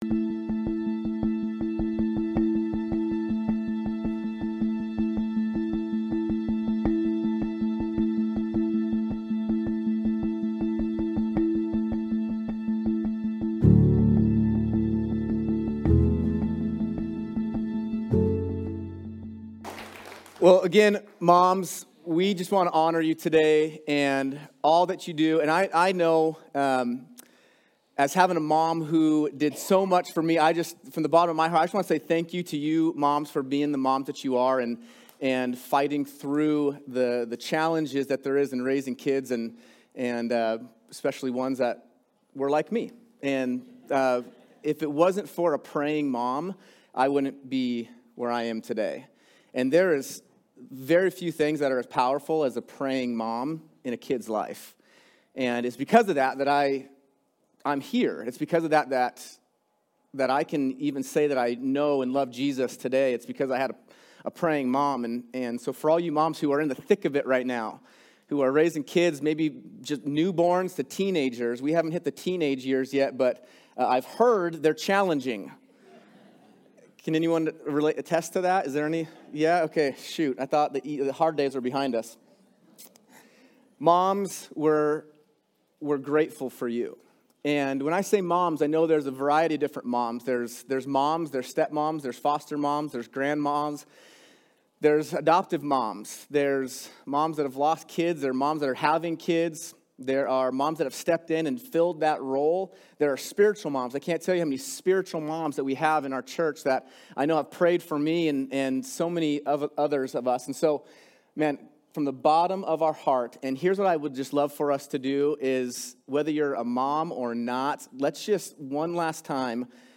Hayden Campus
Sermon